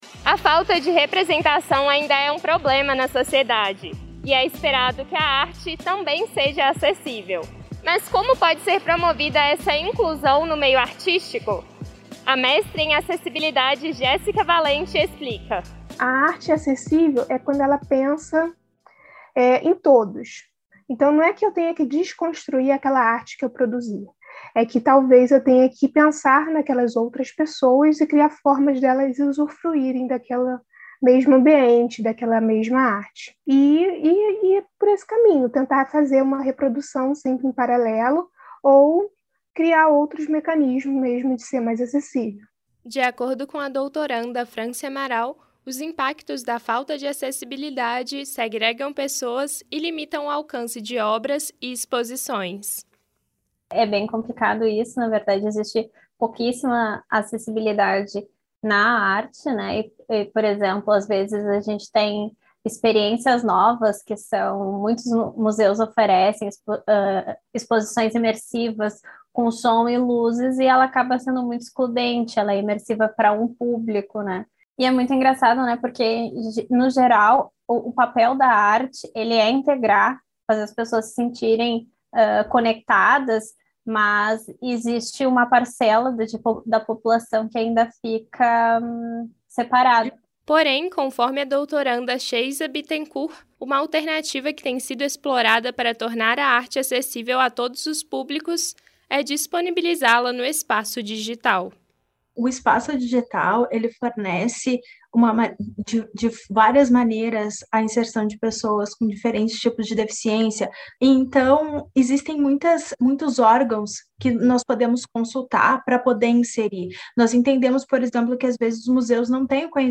ARTE-ACESSIVEL-RADIO.mp3